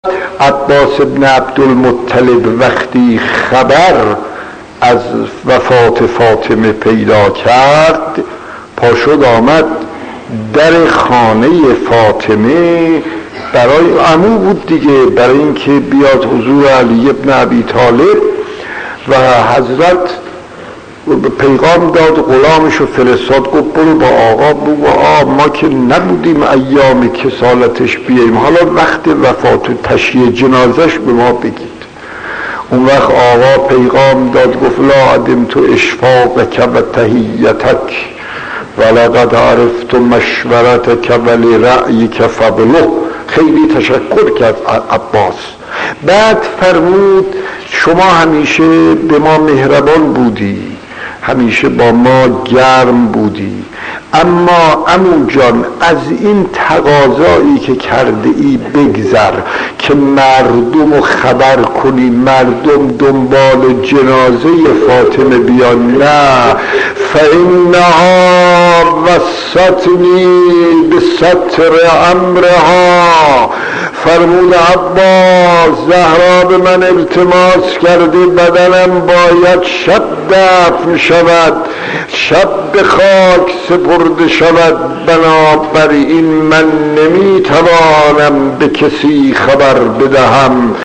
داستان 8 : عباس ابن عبدالمطلب و وفات حضرت زهرا خطیب: استاد فلسفی مدت زمان: 00:01:30